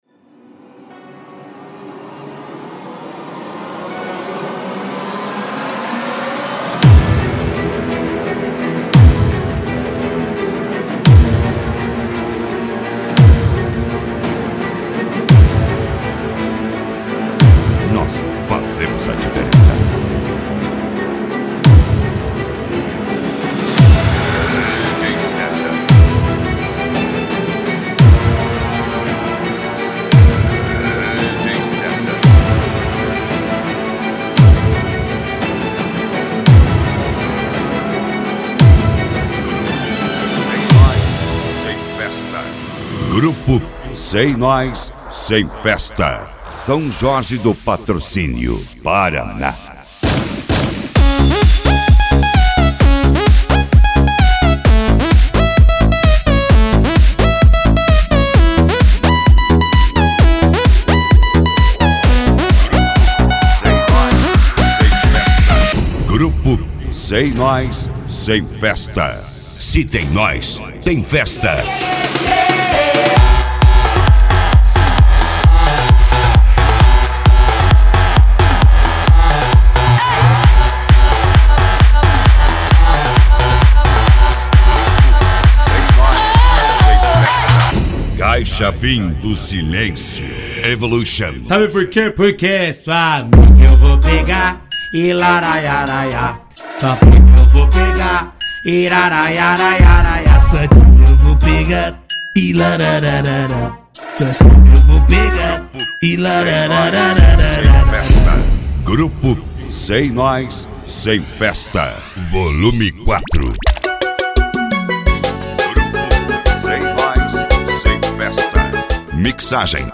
Eletronica
Funk
Funk Nejo
Mega Funk